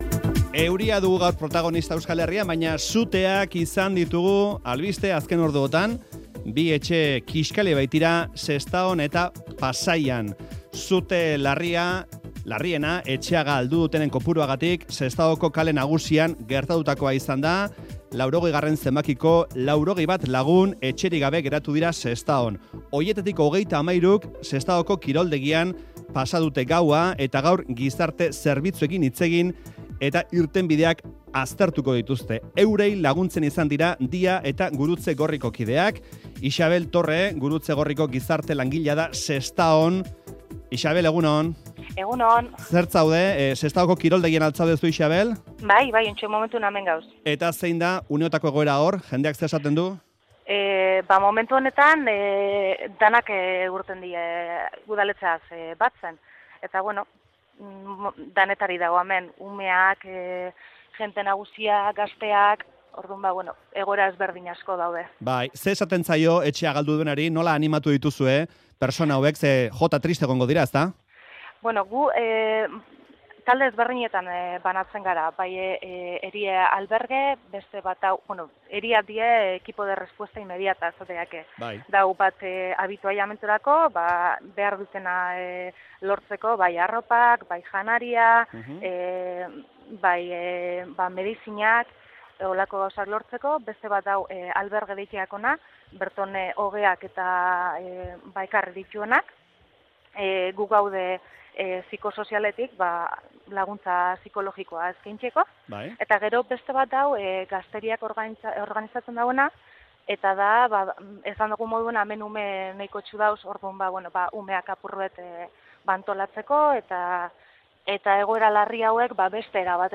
Audioa: Bi etxe kixkali dira azken orduetan Sestaon eta Pasaian. Faktorian bi herrietako testigantzak jaso ditugu, azken orduaren berri eman digute.